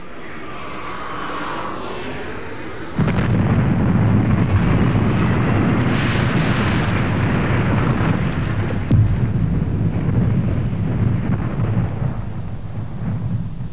دانلود آهنگ طیاره 15 از افکت صوتی حمل و نقل
دانلود صدای طیاره 15 از ساعد نیوز با لینک مستقیم و کیفیت بالا
جلوه های صوتی